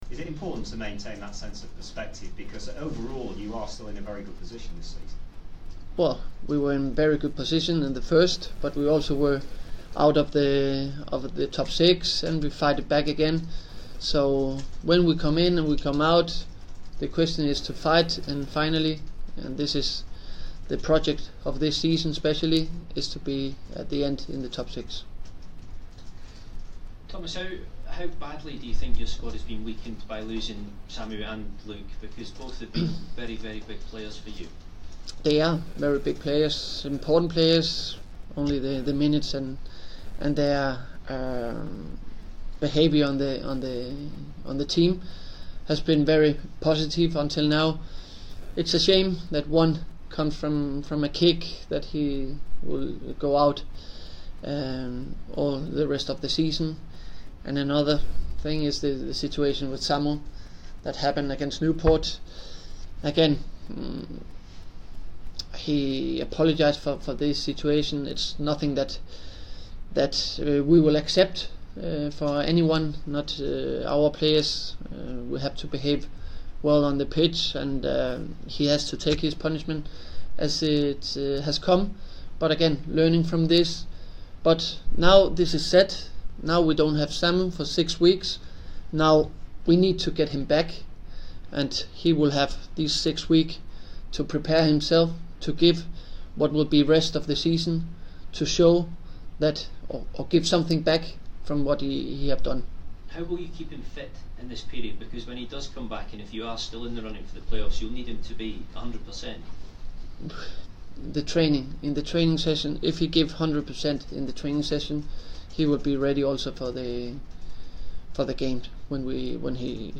Leeds United boss Thomas Christiansen speaks to the media ahead of facing Ipswich Town.